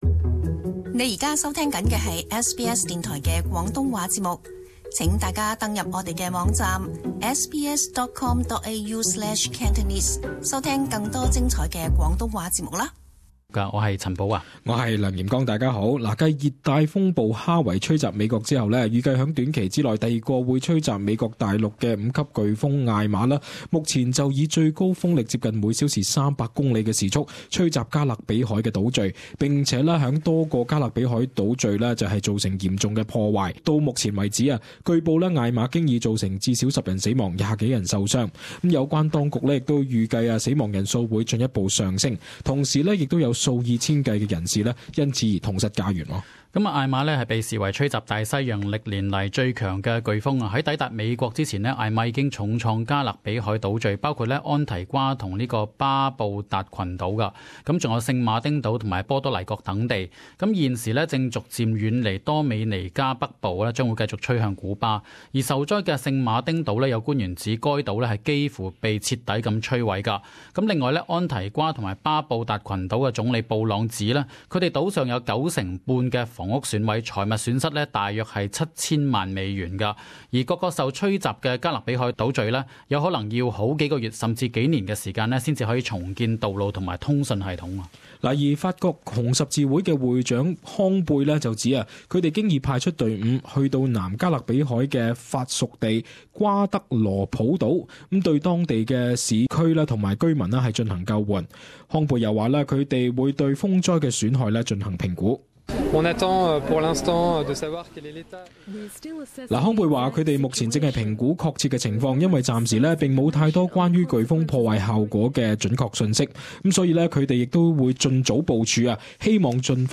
【時事報導】「艾瑪」橫掃加勒比海多個島嶼